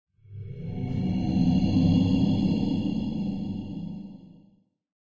cave8.ogg